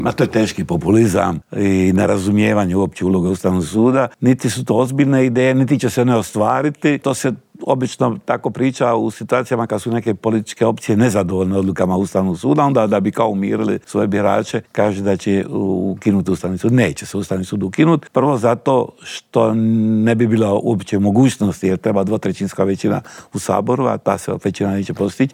Stoga smo u Intervjuu tjedna Media servisa ugostili još uvijek aktualnog predsjednika tog državnog tijela, Miroslava Šeparovića.